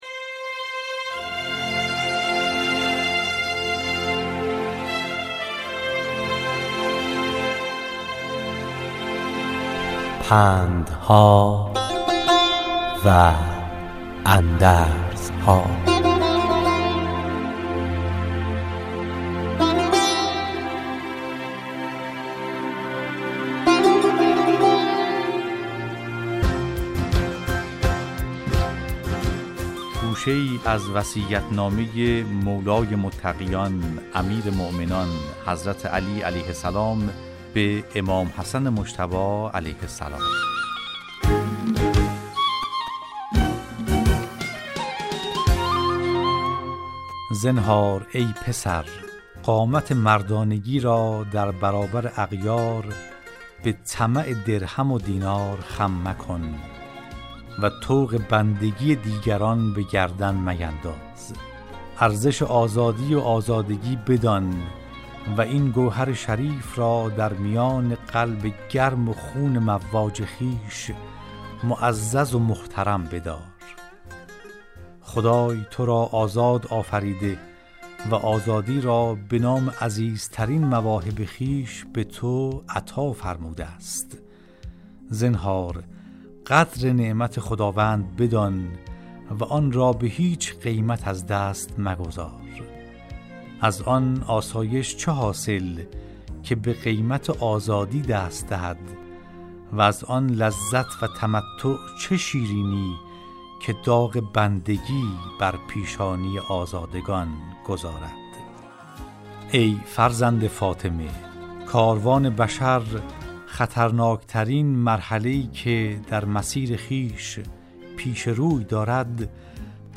راوی برای شنوندگان عزیز صدای خراسان، حکایت های پندآموزی را روایت می کند